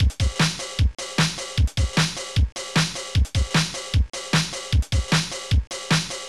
pop&rock2-(127\04-bpm)
Instruments FS680-Bassdrum FS680-Snaredrum.hall B1_Crash FS680-Bassdrum+b1_hh B1_HH